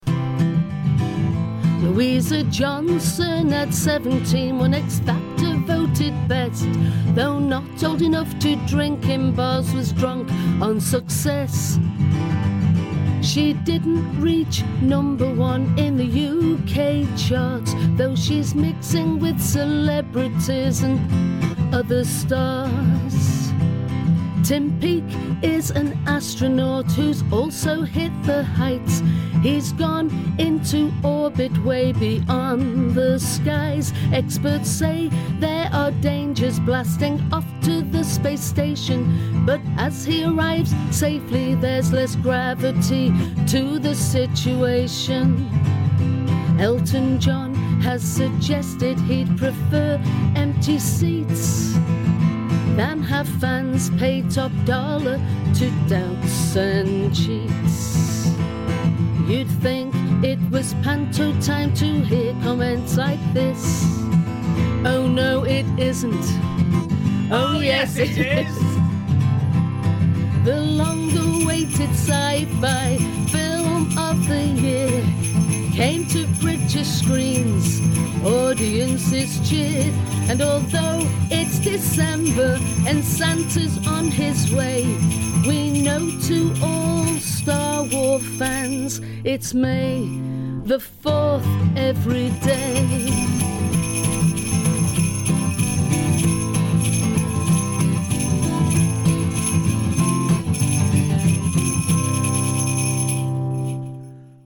news in song